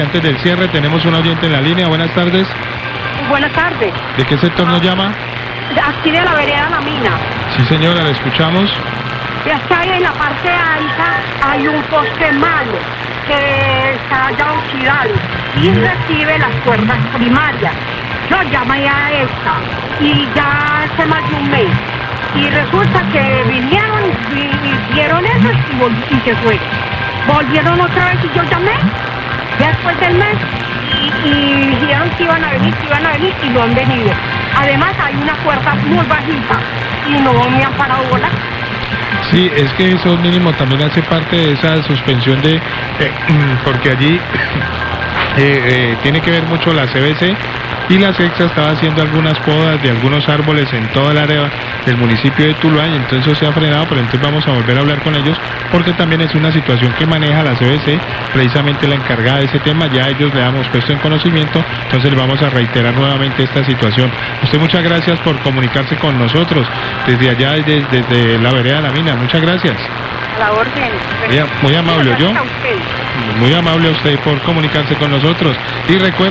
Radio
Oyente de la vereda La Mina se queja de un poste malo que esta oxidado y que recibe las cuerdas primarias, ella dice que llamo a la EPSA hace más de un mes y no le han dado solución.